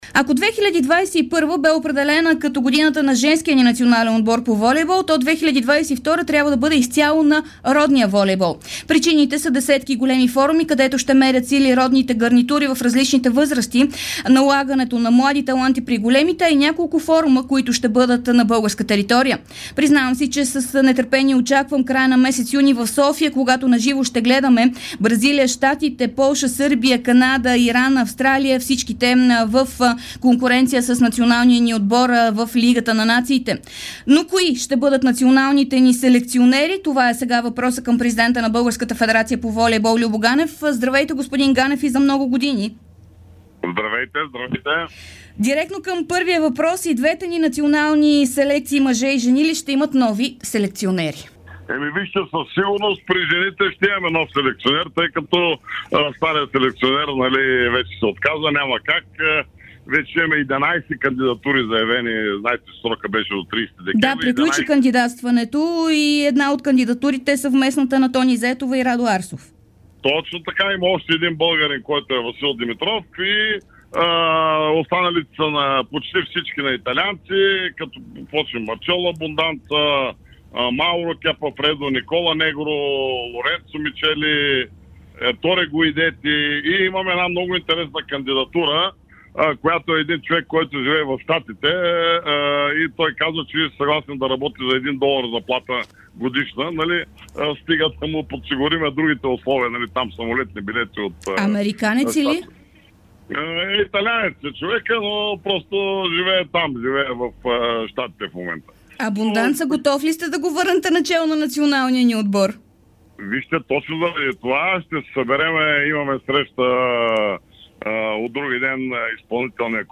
Президентът на федерацията по волейбол Любо Ганев говори специално пред dsport и Дарик радио. Той направи любопитни разкрития около националните селекционери както на мъжкия ни отбор, така и на женския.